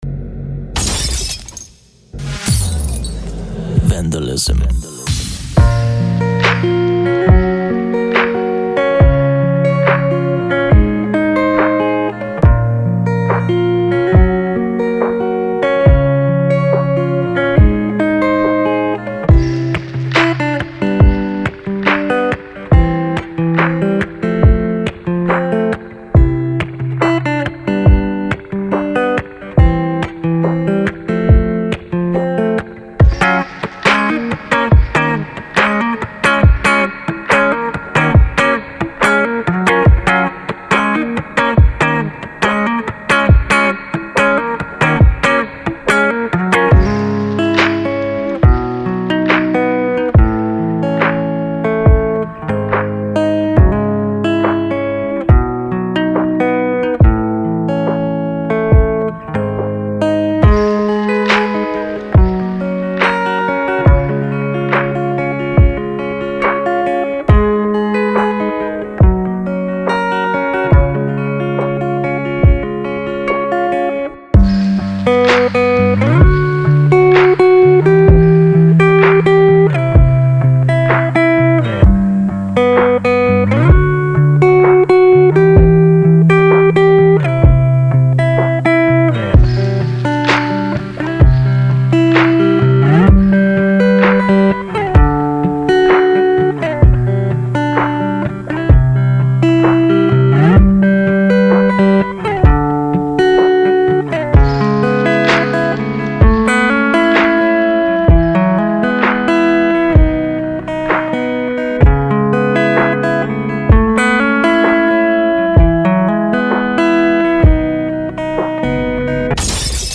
دانلود رایگان مجموعه لوپ گیتارالکتریک